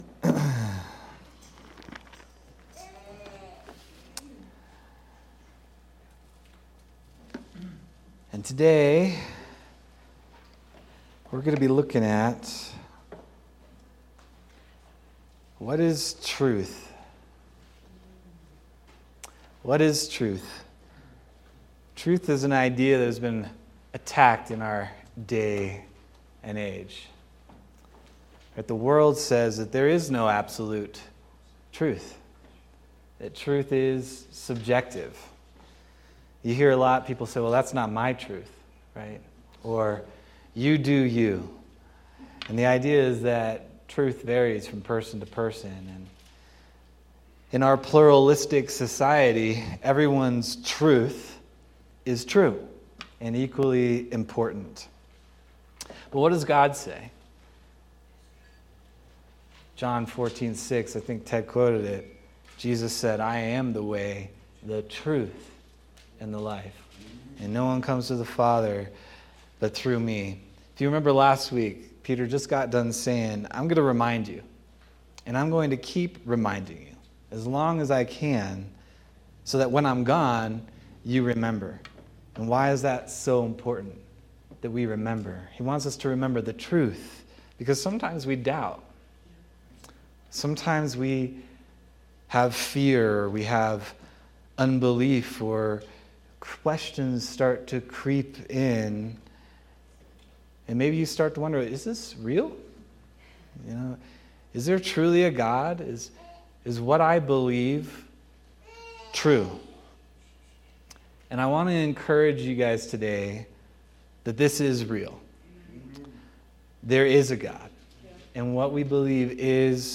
October 12th, 2025 Sermon